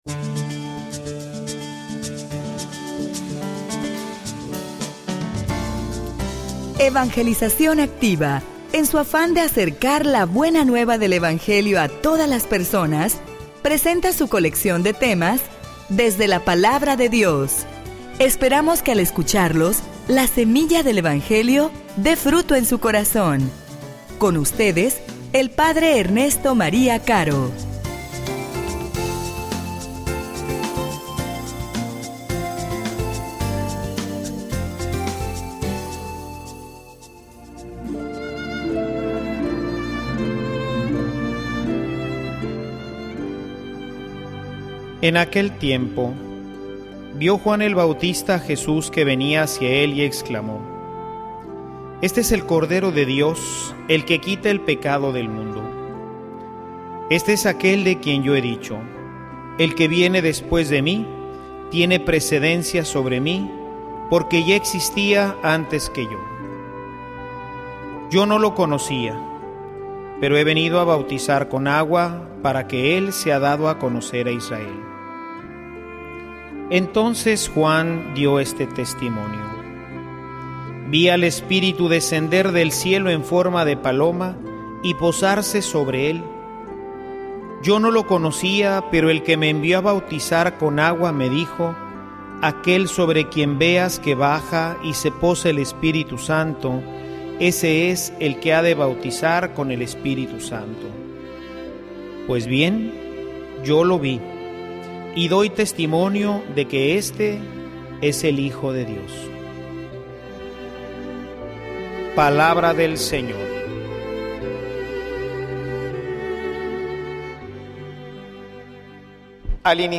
homilia_Y_tu_de_que_eres_testigo.mp3